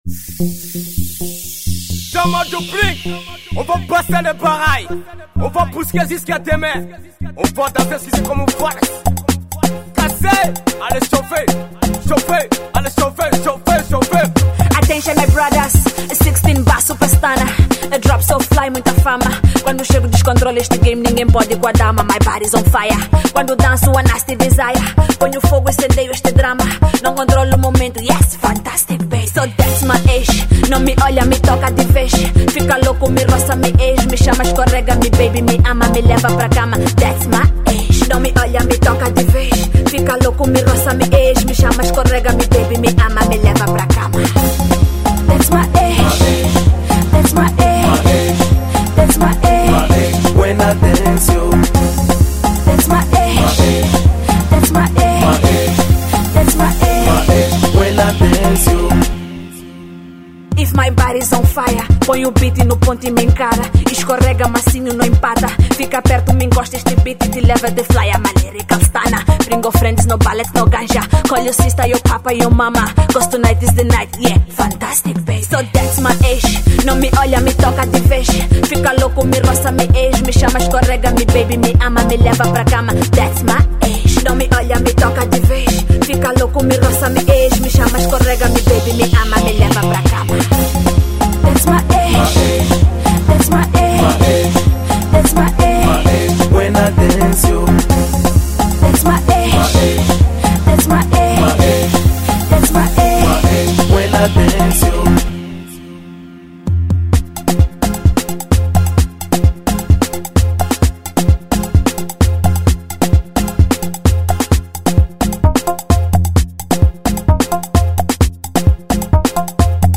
native Kuduro-inspired Afro Pop